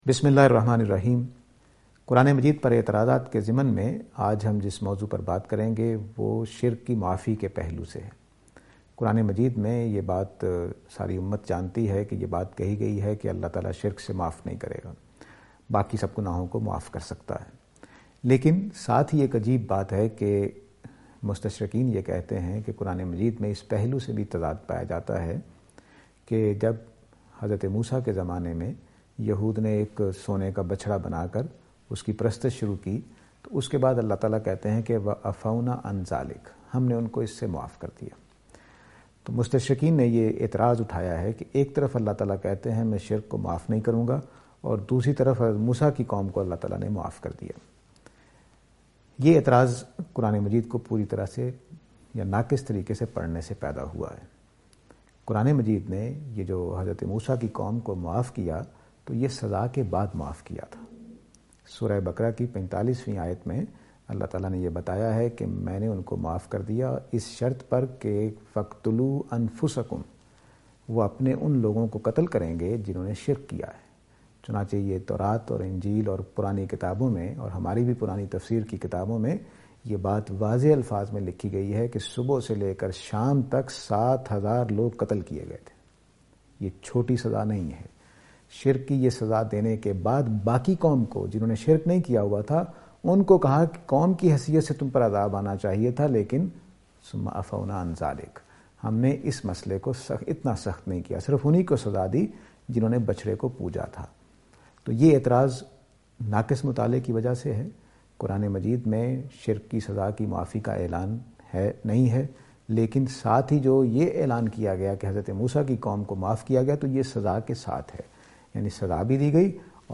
This lecture will present and answer to the allegation "Vindication of shirk?".